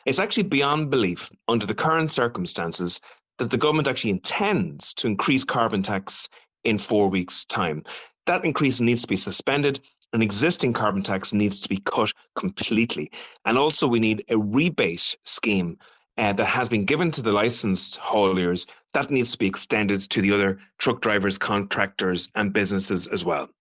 Aontu leader Peadar Tobin says today’s protest is no surprise.